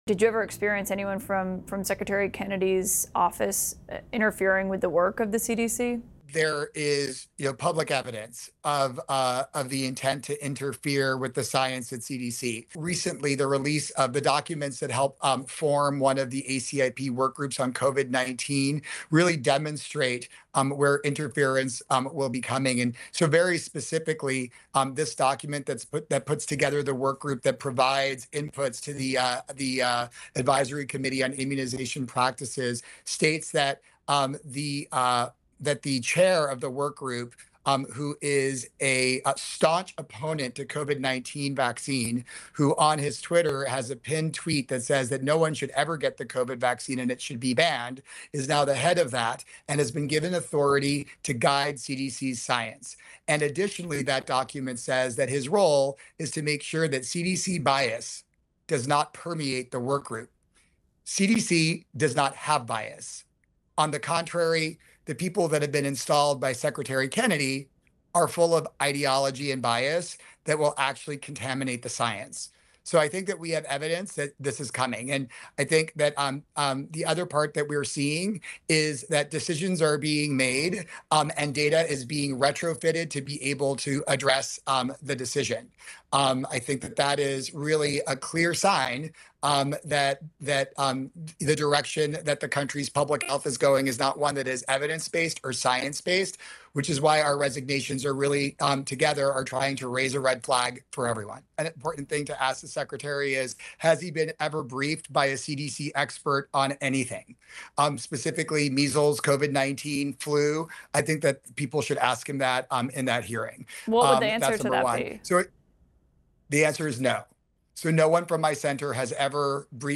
CNN’s Kaitlan Collins speaks with sound effects free download By cnn 97 Downloads 1 days ago 140 seconds cnn Sound Effects About CNN’s Kaitlan Collins speaks with Mp3 Sound Effect CNN’s Kaitlan Collins speaks with a former CDC leader who resigned after decades of public service in the wake of CDC Director Dr. Susan Monarez’s departure amid clashes with HHS Secretary Robert Kennedy Jr. over vaccine policy.